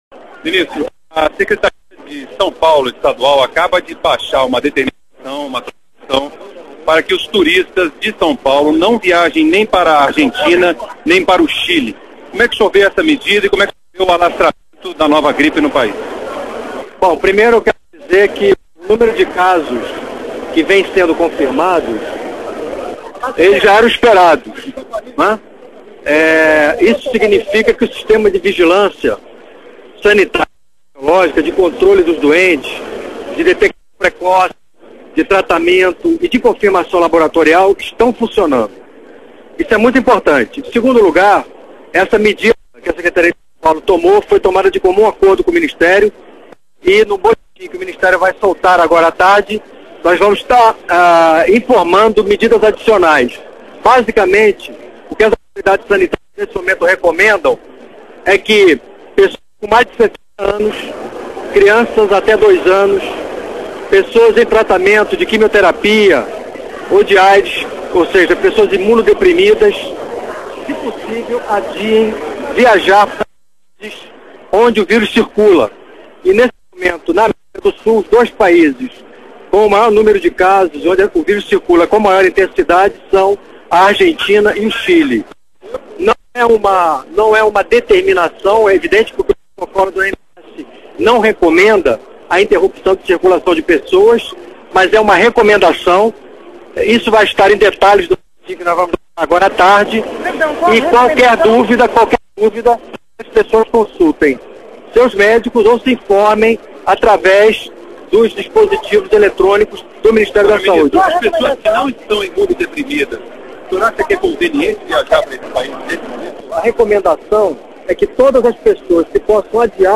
Ministro fala sobre a gripe A (H1N1)
O site oficial do Ministério da Saúde do Brasil traz, em sua primeira página, um link para o comunicado do Ministro Temporão acerca dos aumento de casos da nova gripe no país.